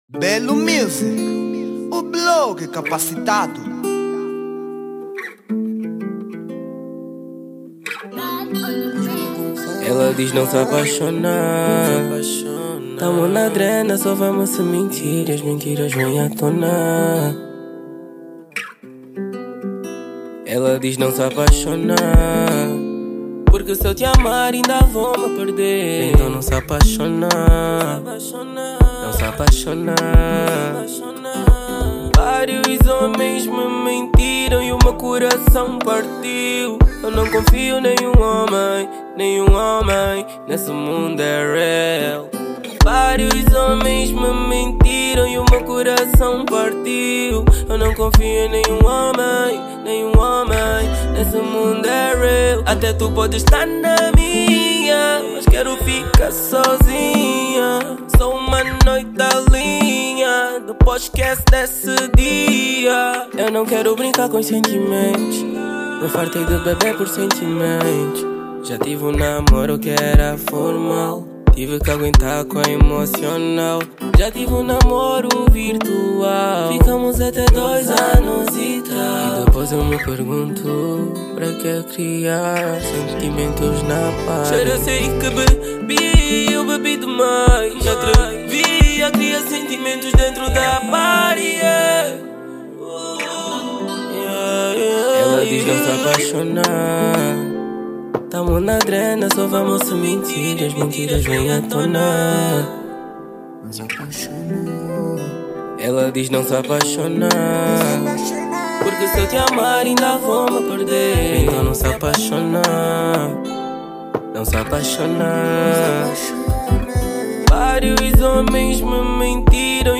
Género : Zouk